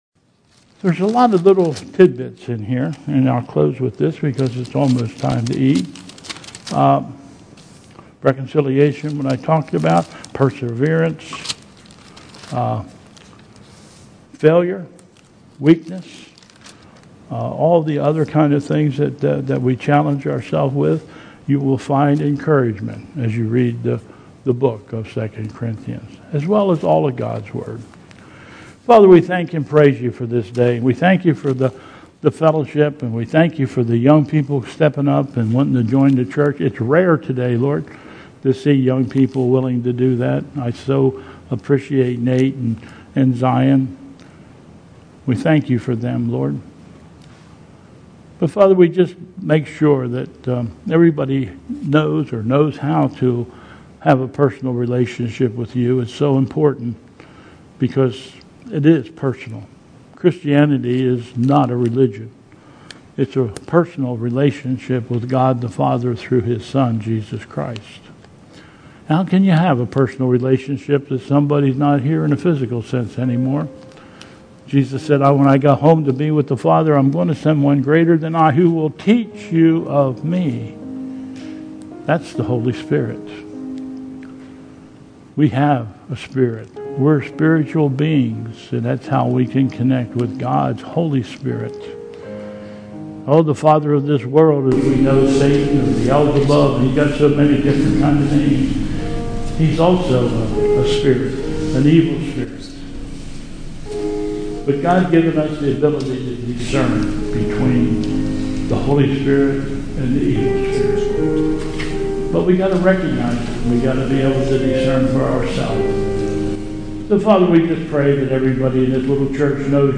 Bethel 03/08/2020 Service
Verse - Prelude/Processional ....